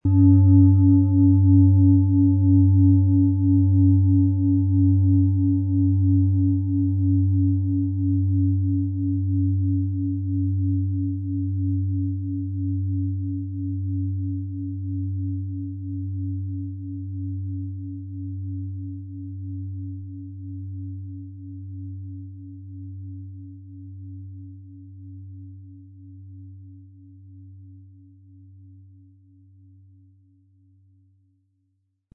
Nach uralter Tradition von Hand getriebene Klangschale.
• Mittlerer Ton: Pluto
• Höchster Ton: OM-Ton
PlanetentöneBiorythmus Geist & Pluto & OM-Ton (Höchster Ton)
MaterialBronze